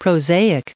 added pronounciation and merriam webster audio
1010_prosaic.ogg